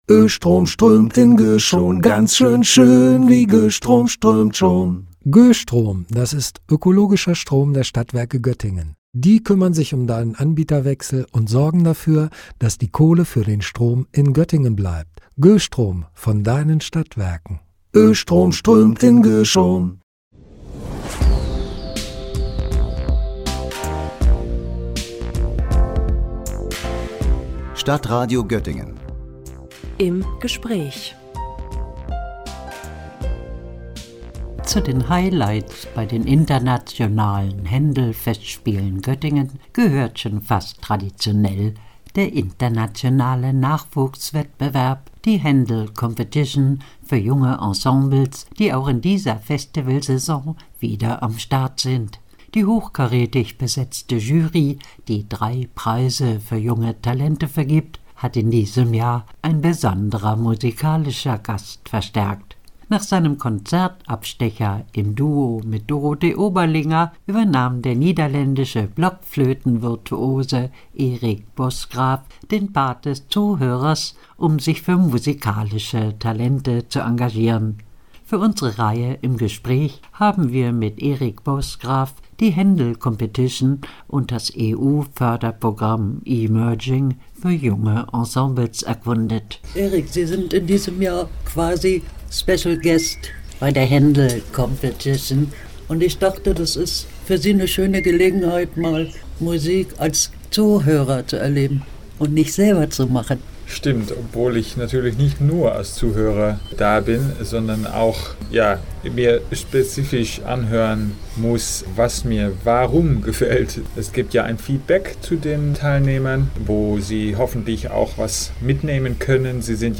„Händel-Kaleidoskop“ – Gespräch